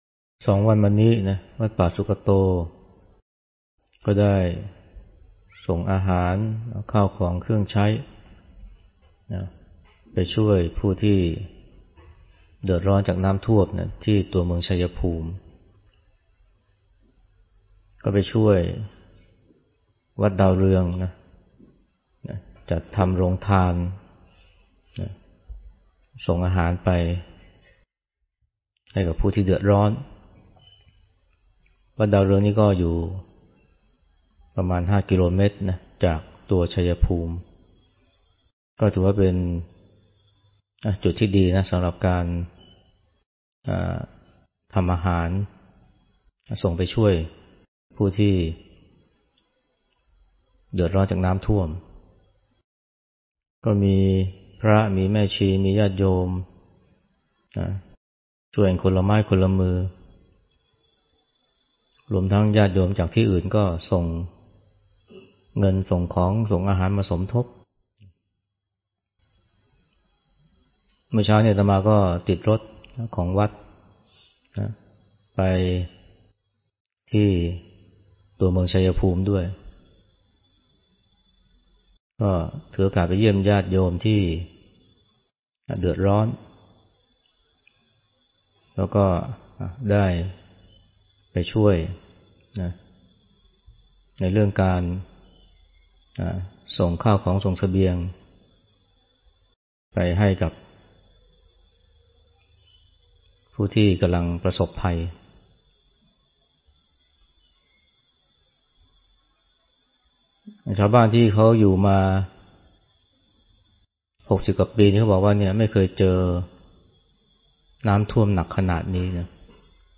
ฟังธรรมะ Podcasts กับ พระอาจารย์ไพศาล วิสาโล